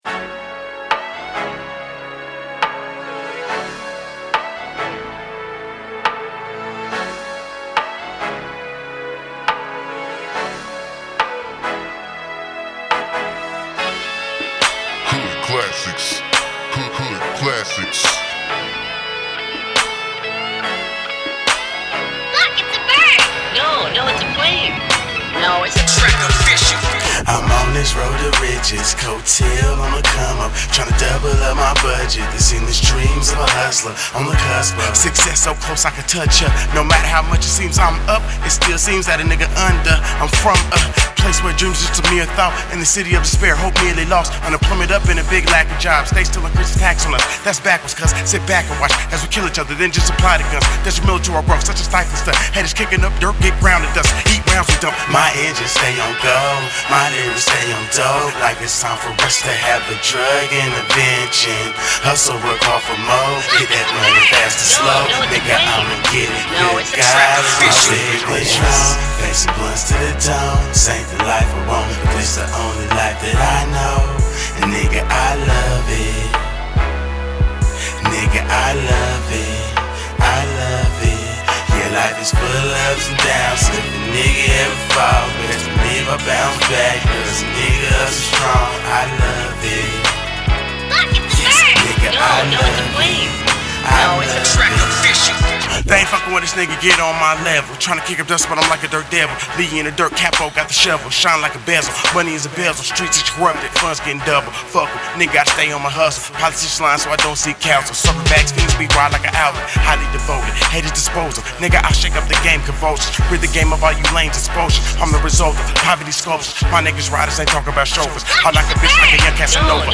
rap mixtape
hip hop